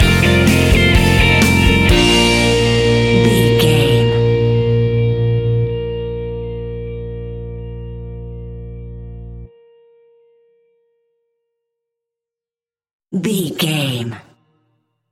Ionian/Major
B♭
indie pop
energetic
uplifting
cheesy
instrumentals
guitars
bass
drums
piano
organ